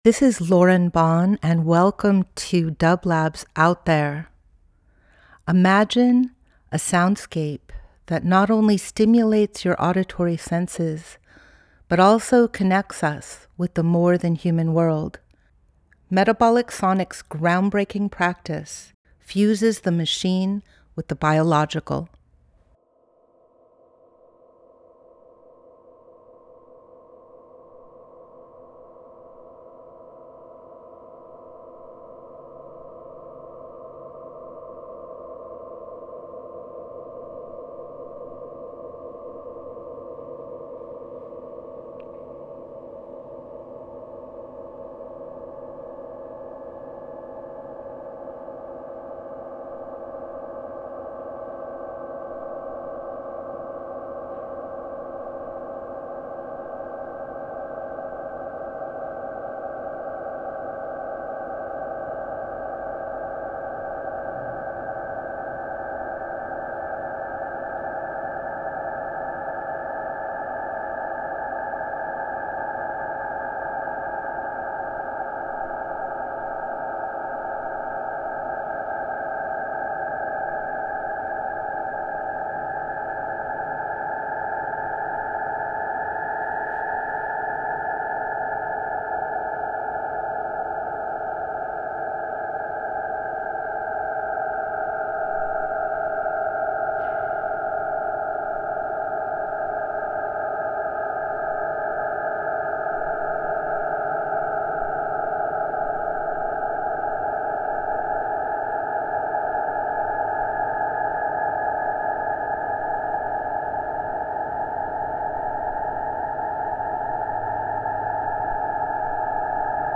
Each week we present a long-form field recording that will transport you through the power of sound.
These are recordings of an 80’ tall silo strung with cables to make a massive aeolian harp, located 300 miles north of Los Angeles and Metabolic Studio. It was created in March 2013 and has amplified the sonic river flowing between Yaagna (or the LA Basin) and Payahuunadu (or Owens Valley) since then.